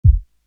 Pointer Small Kick.wav